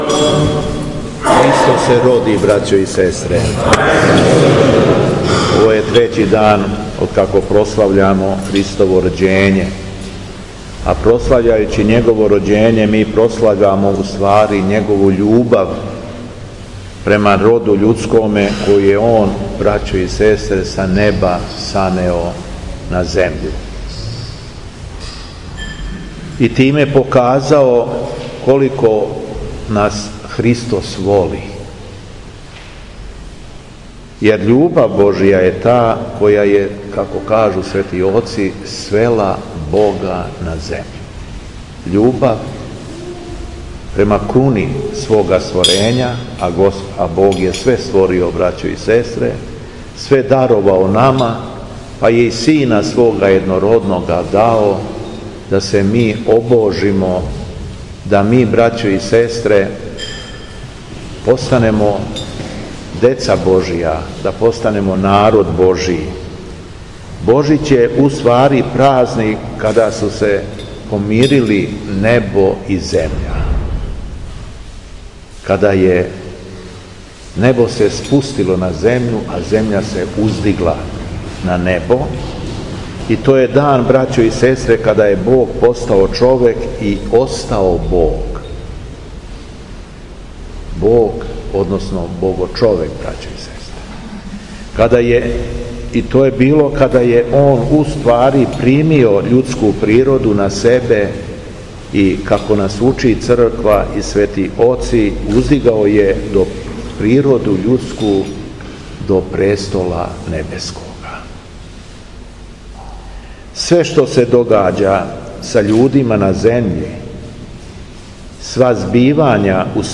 Звучни запис беседе